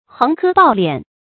橫科暴斂 注音： ㄏㄥˋ ㄎㄜ ㄅㄠˋ ㄌㄧㄢˇ 讀音讀法： 意思解釋： 同「橫征暴賦」。